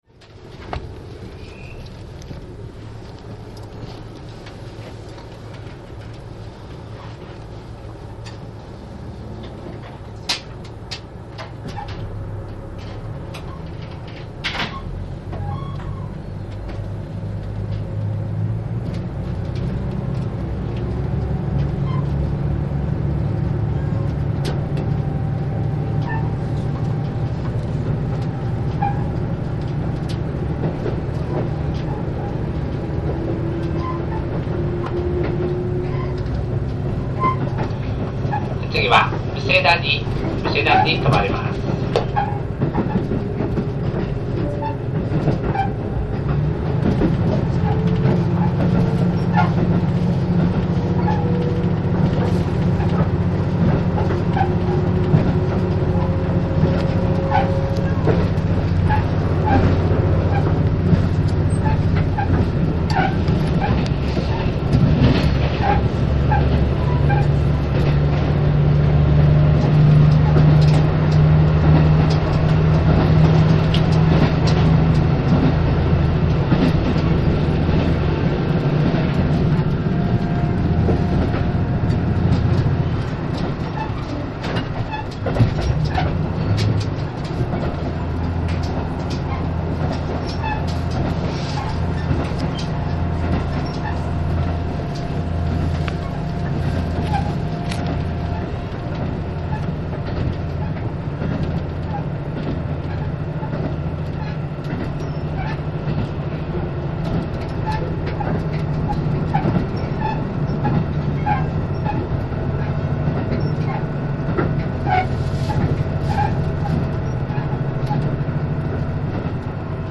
ファイルは全部MP-3でステレオです。
紀伊長島を出て荷坂峠を登るキハ８５です。ファイルの２分４０秒を過ぎたあたりから峠の登りこう配が始ります。
本来ならもっと快速で駆け上がる所ですが、なにぶんカーブが多くてスピードは抑え気味に登ります。
近くの座席に猫を連れた人がいて、その猫が鳴きどおし。